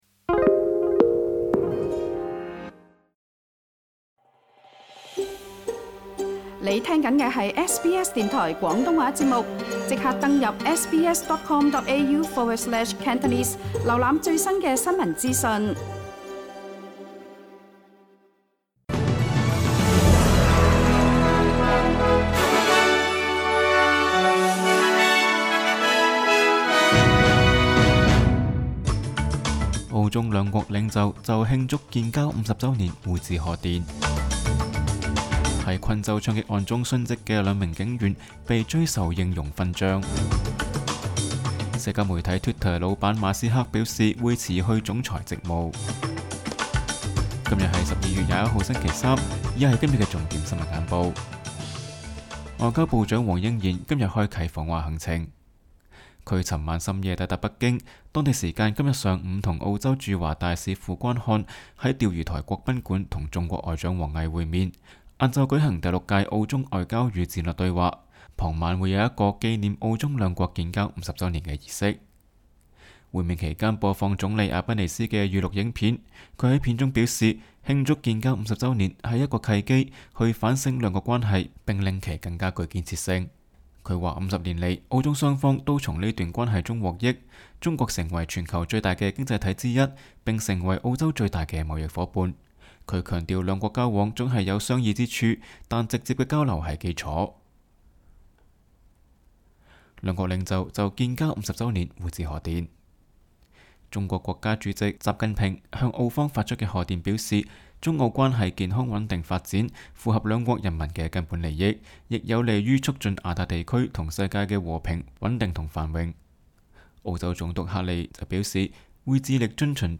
SBS 廣東話節目新聞簡報